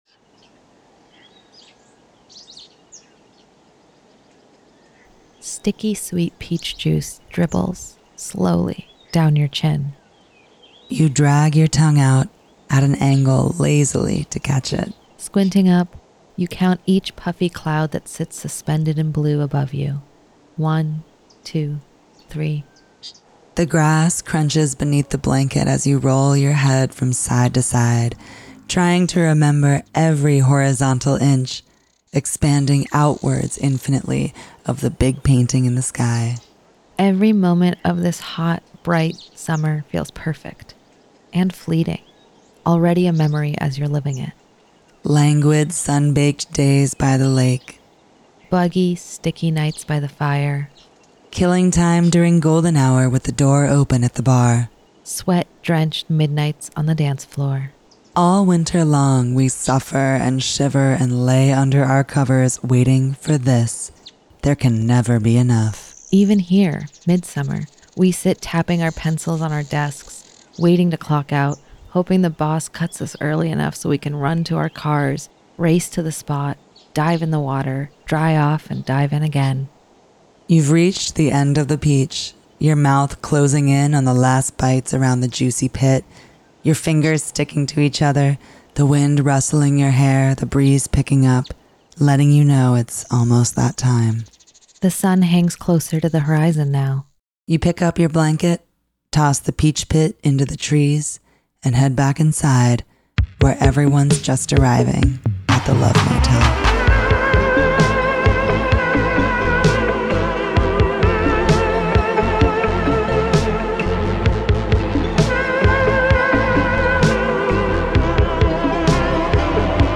The Love Motel is a monthly late-night radio romance talk show with love songs, relationship advice, and personals for all the lovers in the upper Hudson Valley.